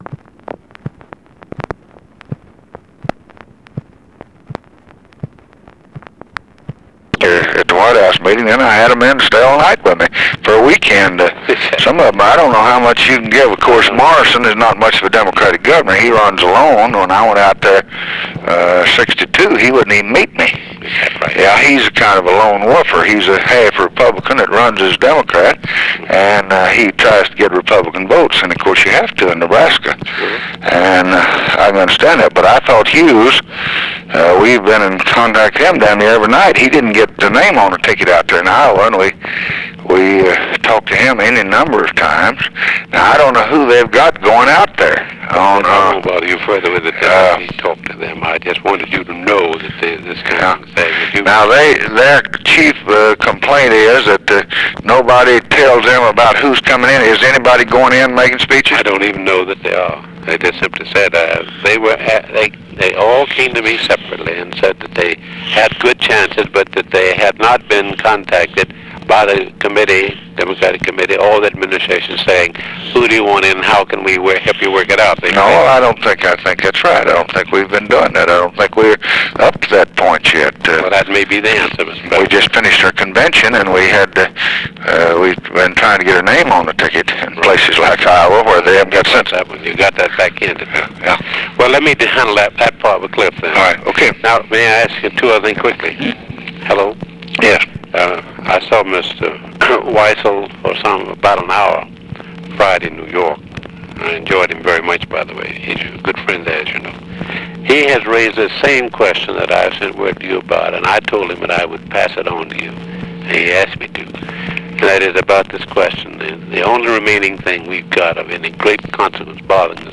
Conversation with LUTHER HODGES, September 14, 1964
Secret White House Tapes